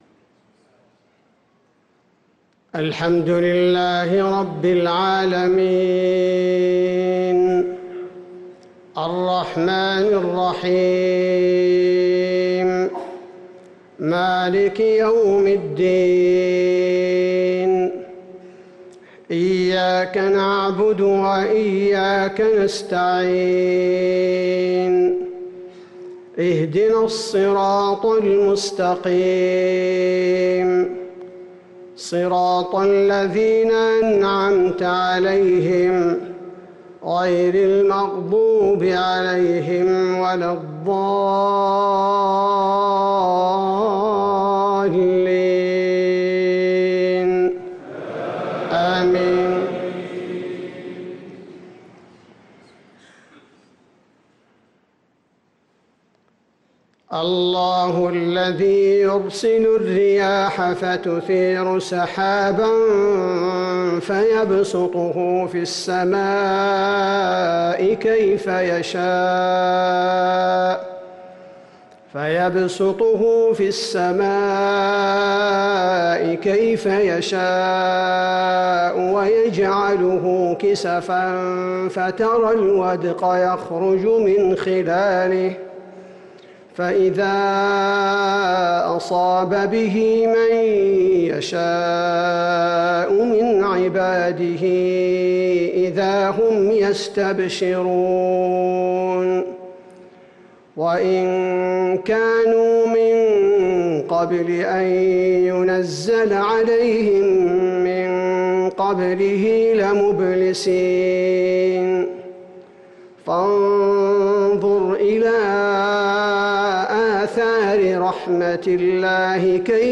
صلاة العشاء للقارئ عبدالباري الثبيتي 28 جمادي الأول 1445 هـ
تِلَاوَات الْحَرَمَيْن .